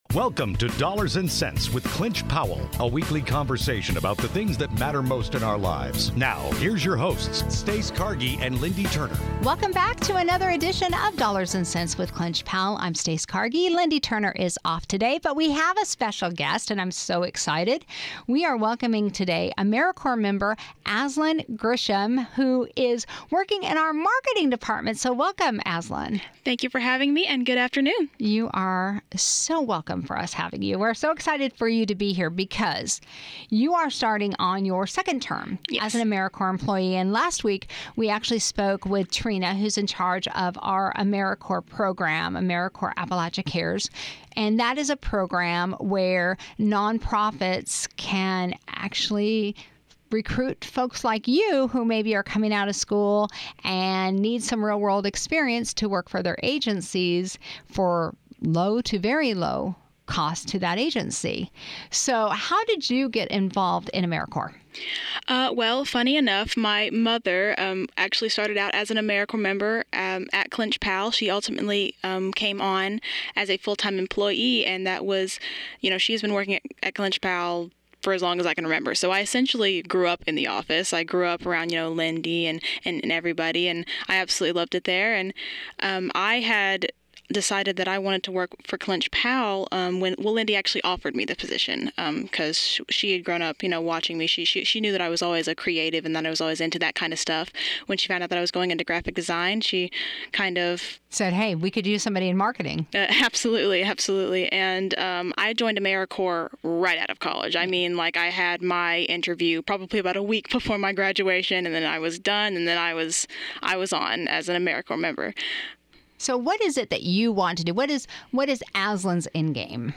Dollars and Sense Radio Show Ep. 43: Professional Social Media - Clinch-Powell RC&D